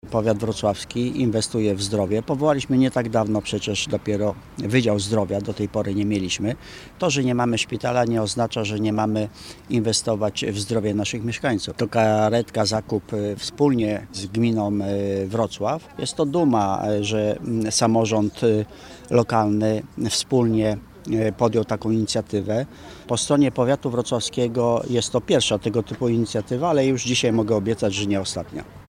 – Najważniejsze zdrowie dla każdego człowieka jest zdrowie – mówi Hieronin Kuryś, członek zarządu powiatu wrocławskiego.